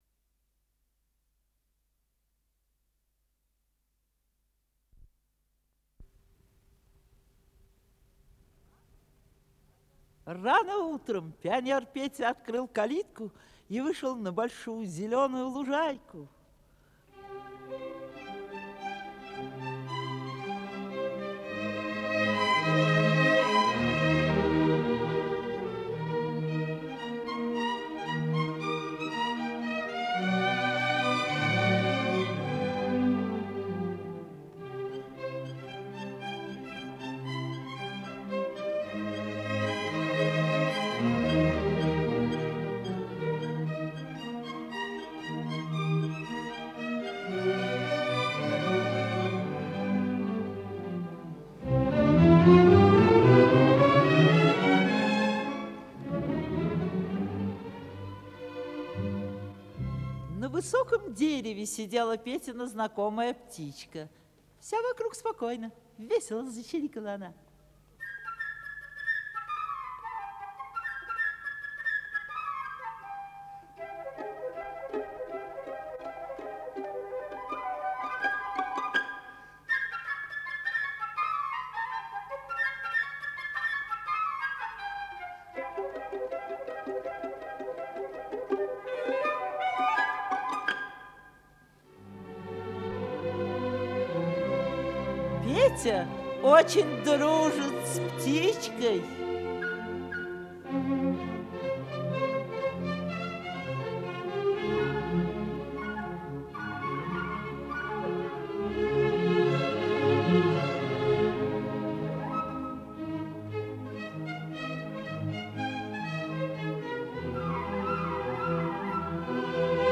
Симфоническая сказка для детей